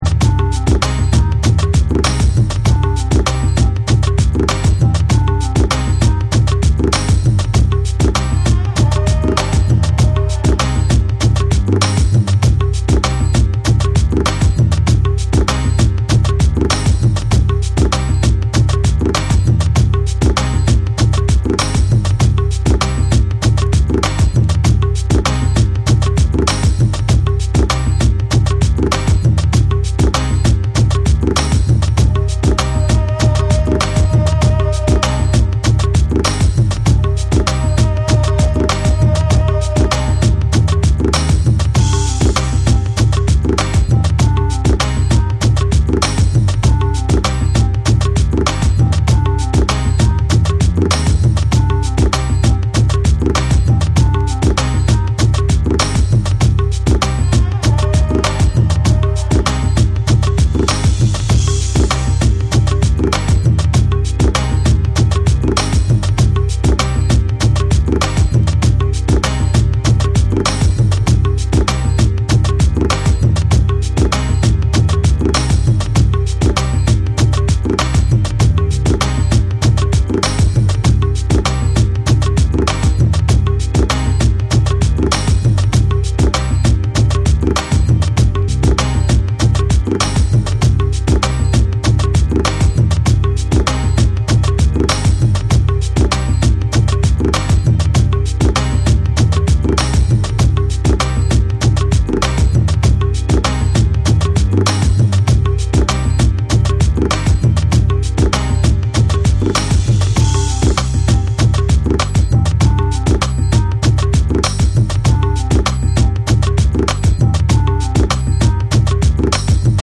REGGAE & DUB / BREAKBEATS